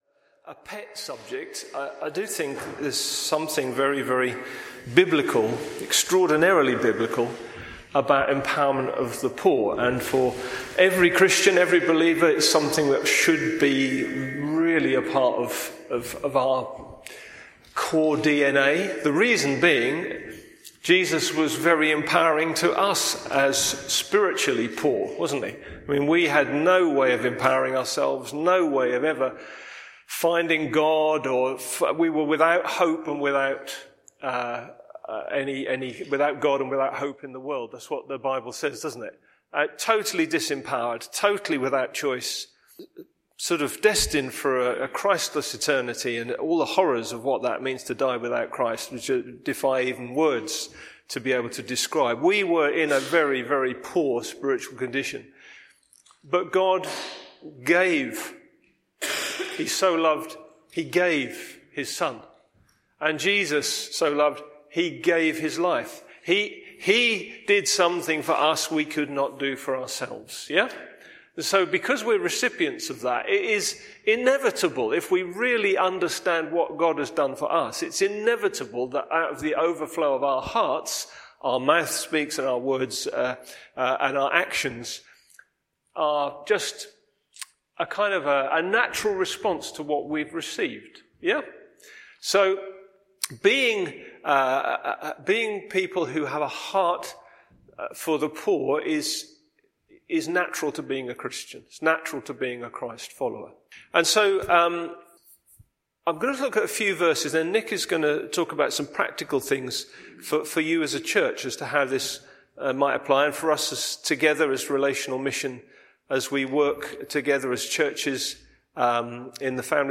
Empowerment of the poor, Bassett Street Sermons